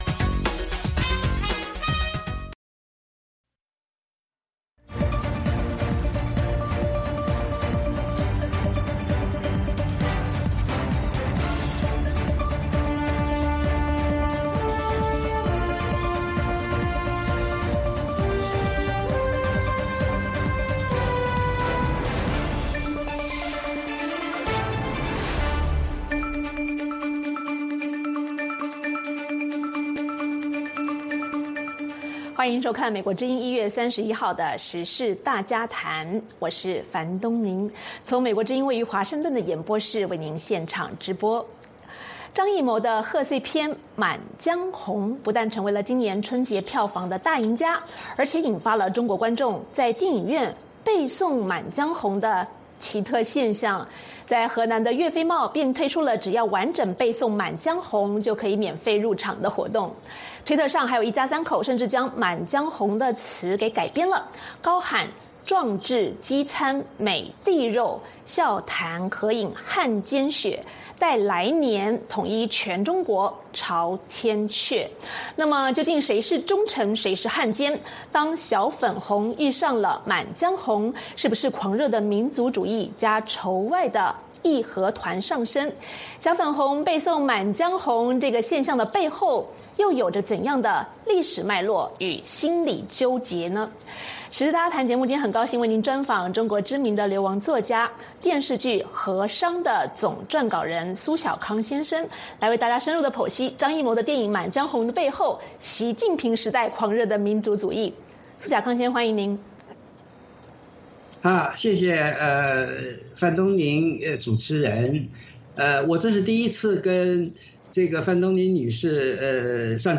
VOA卫视-时事大家谈：专访苏晓康（上）：当“小粉红”遇上《满江红》；专访苏晓康（下）：民族主义—习近平的续命丸？